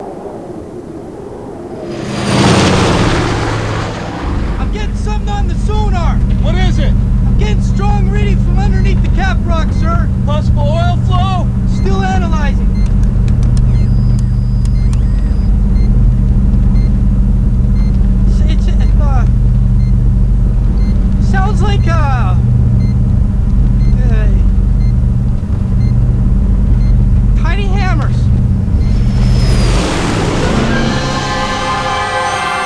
In fact, his are the first face to be seen and the first voice to be heard in the movie.
Here are the opening lines of the movie.